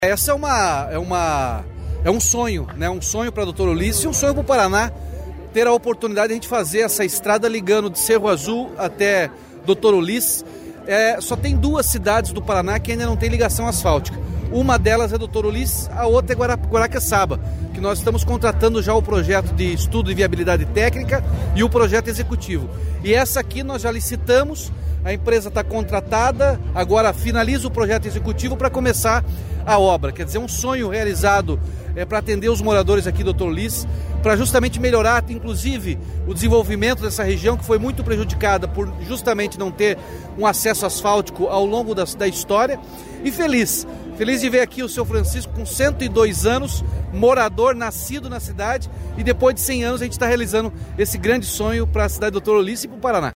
Sonora do governador Ratinho Junior sobre a assinatura da ordem de serviço da pavimentação da PR-092 até Doutor Ulysses